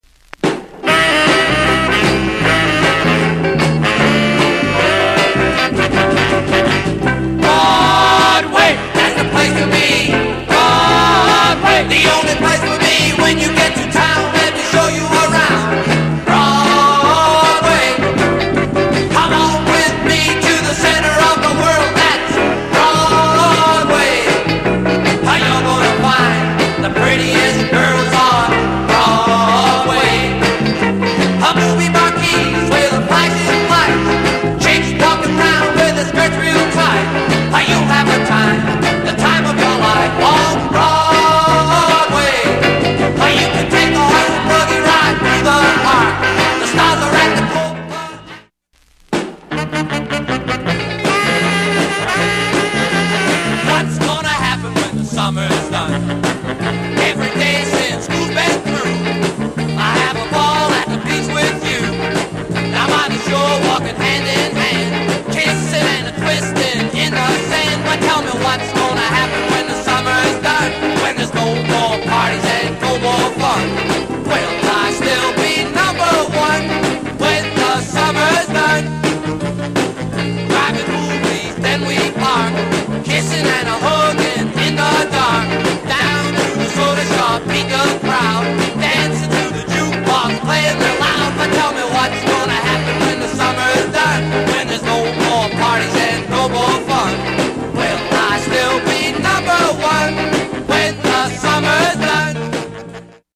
US 50's Rockabilly / R&R US盤
ノリの良いRock'n'Roll